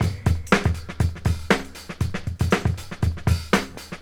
• 119 Bpm Modern Drum Loop Sample G Key.wav
Free breakbeat - kick tuned to the G note. Loudest frequency: 1074Hz
119-bpm-modern-drum-loop-sample-g-key-1bq.wav